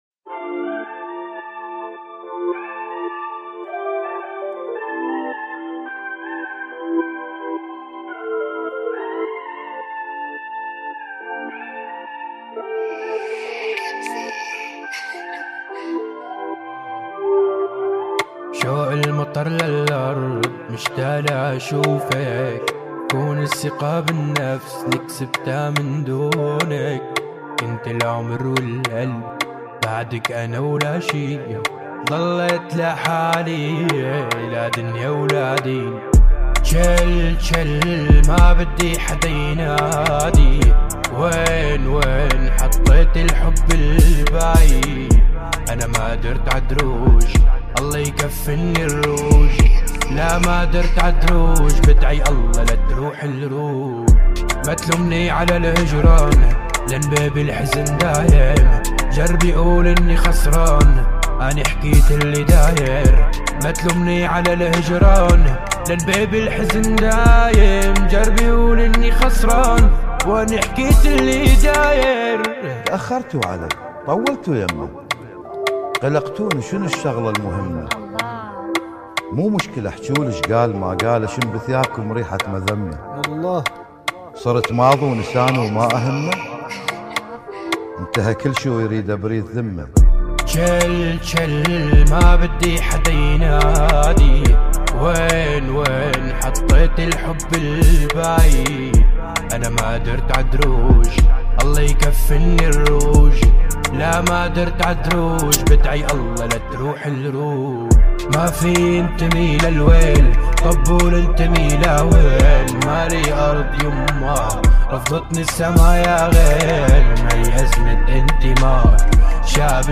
• اللون الموسيقي: بوب بديل / حزين (Melancholic Pop).